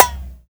Snares
SNARE.82.NEPT.wav